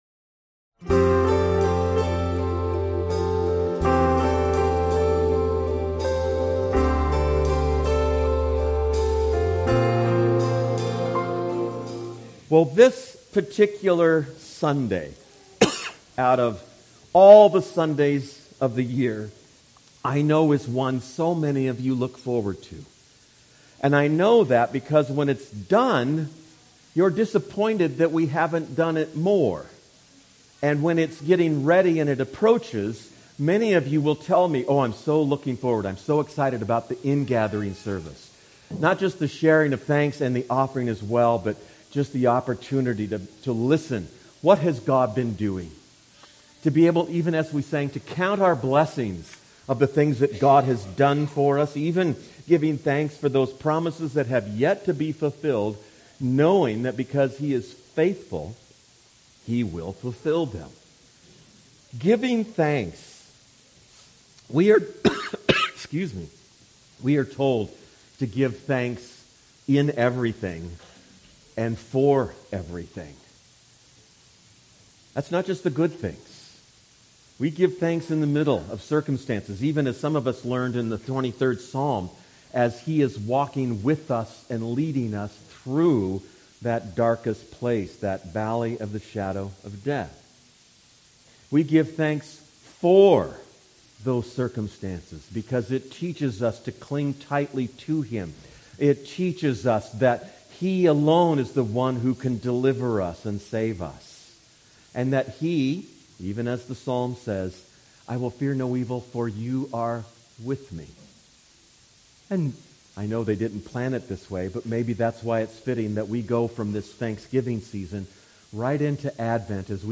Today was our Annual Ingathering Service, during which the people known as Albion proclaim God's goodness, faithfulness, and grace through testimonies of thanksgiving. Hopefully, the audio will come through, even though our camera cannot move to capture those who are sharing. Some of the singing and ministry in music needed to be left out due to copyright issues.